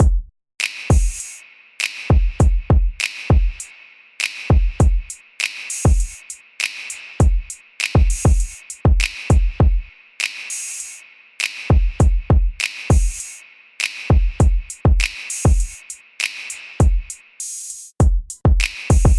描述：速度：100bpm一些扭动型的鼓用于黄魔鬼的循环。
Tag: 100 bpm Chill Out Loops Drum Loops 3.23 MB wav Key : Unknown